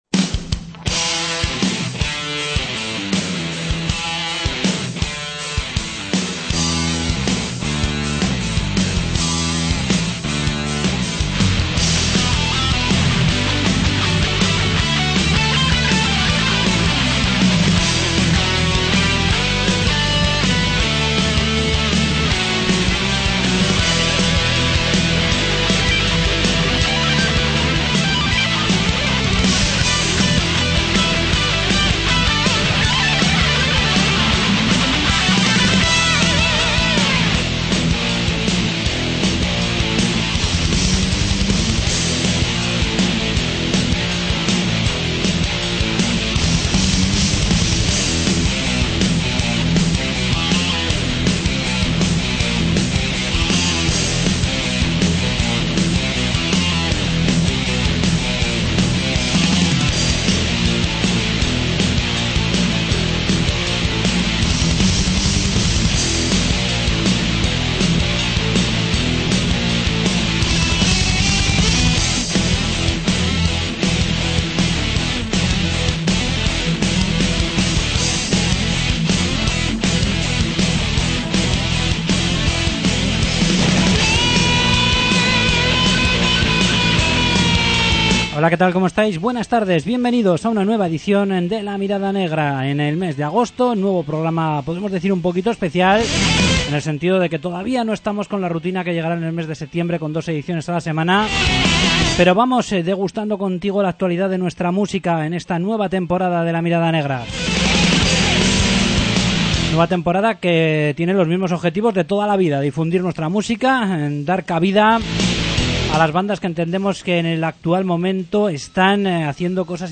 Entrevista con Eternal Psycho